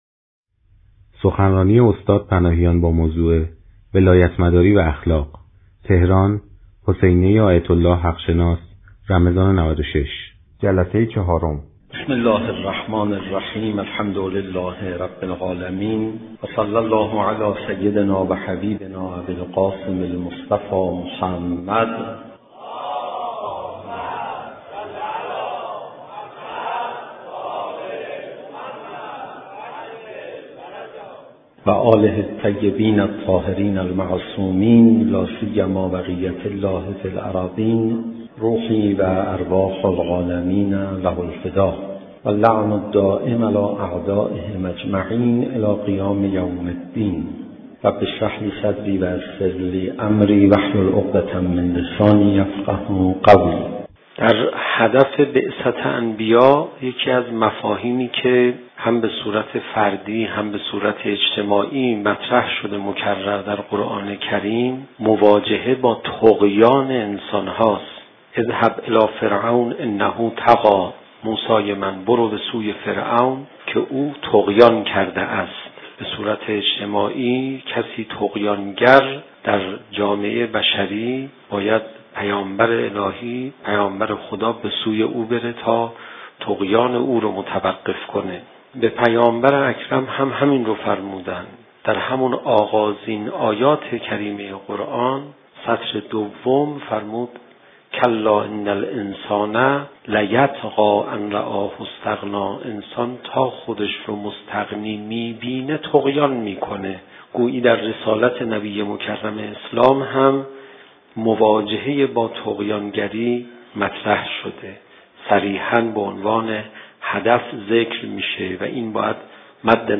شب 19 رمضان(شب قدر)_حسینیه حق شناس_ولایتمداری و اخلاق